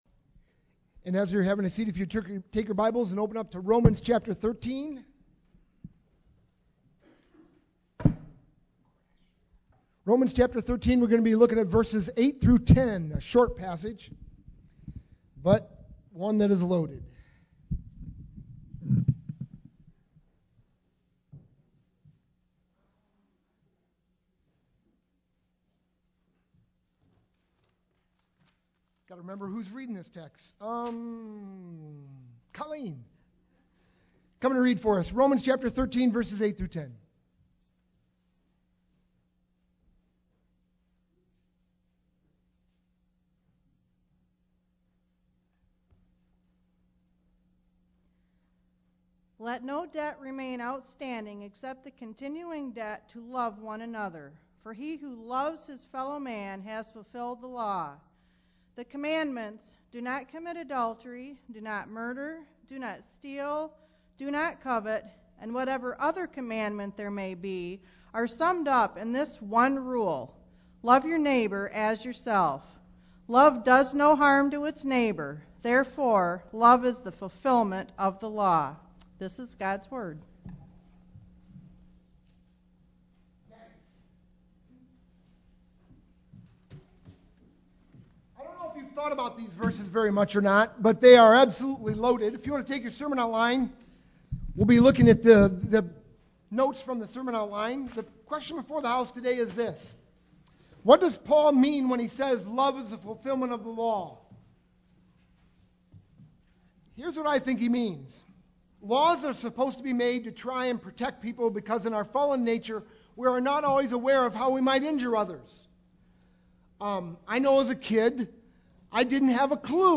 sermon-1-22-12.mp3